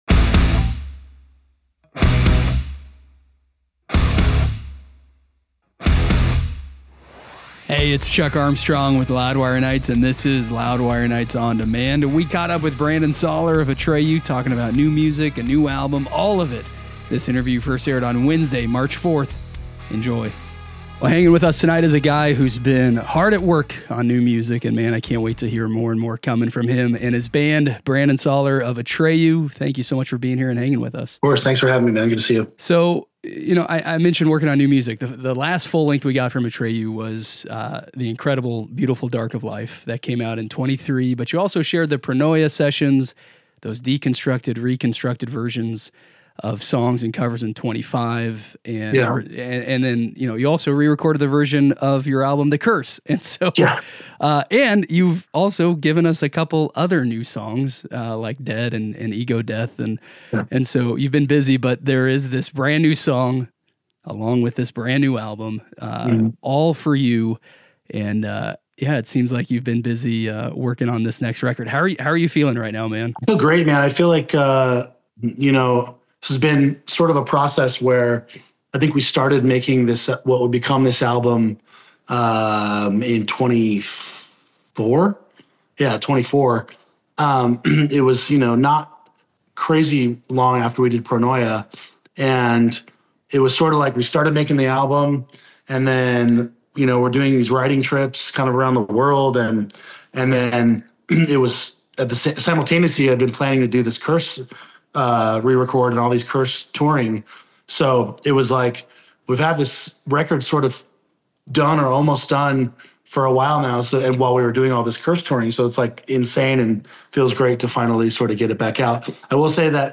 Brandon Saller Discusses Atreyu's Next Album, Band's Legacy - Interview